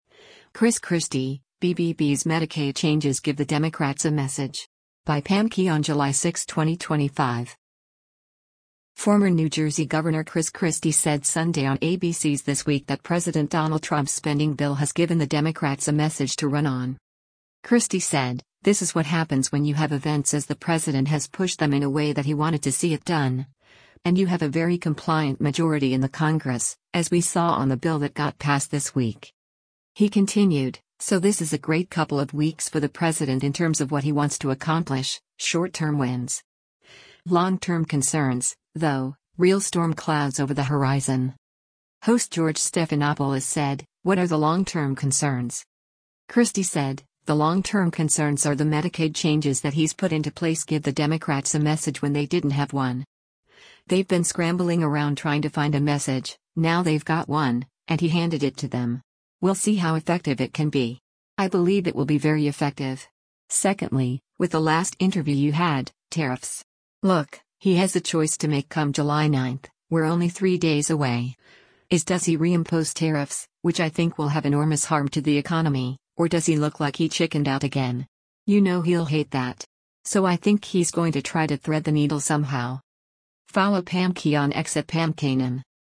Former New Jersey Gov. Chris Christie said Sunday on ABC’s “This Week” that President Donald Trump’s spending bill has given the Democrats a message to run on.